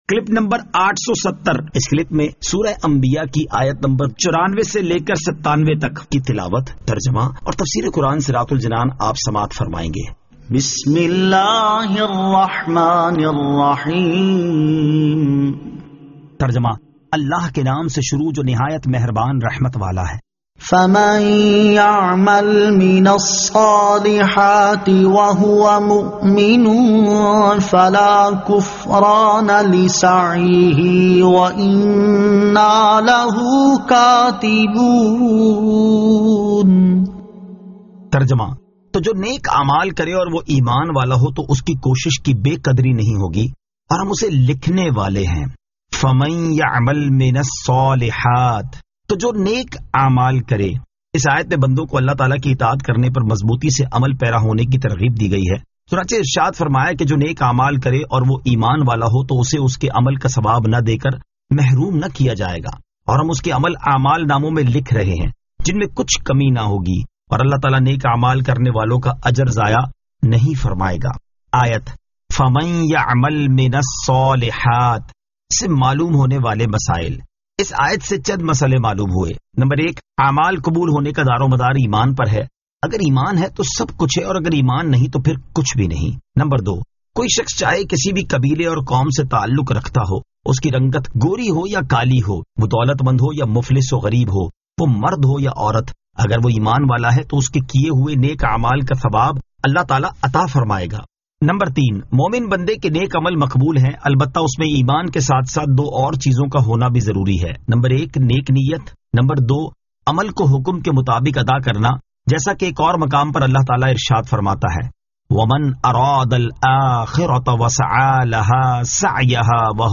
Surah Al-Anbiya 94 To 97 Tilawat , Tarjama , Tafseer